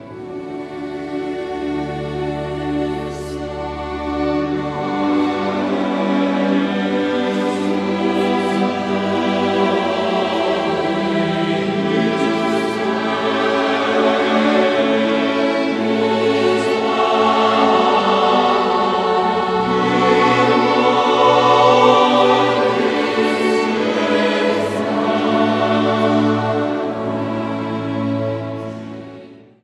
Category: Classical music ringtones